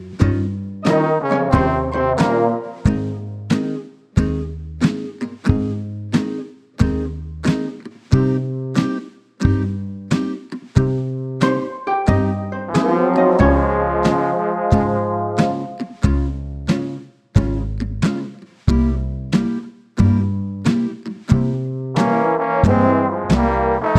no Backing Vocals Oldies (Female) 2:56 Buy £1.50